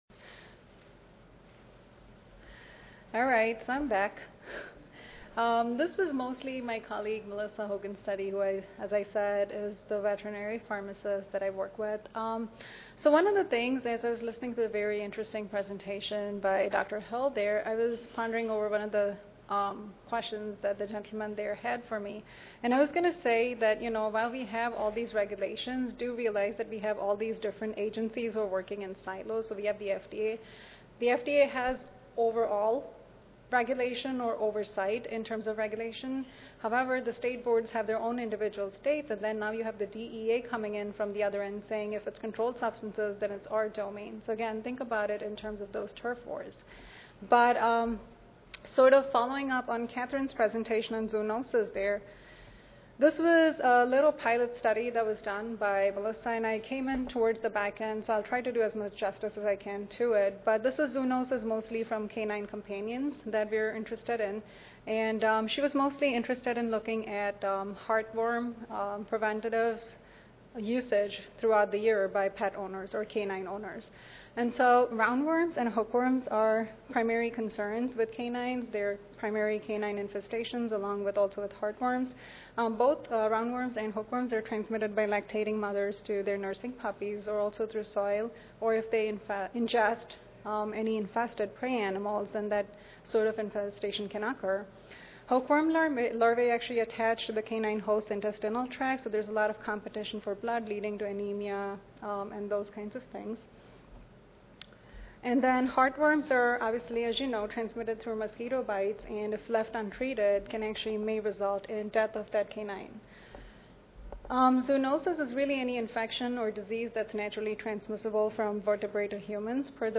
141st APHA Annual Meeting and Exposition (November 2 - November 6, 2013): One Health- National and International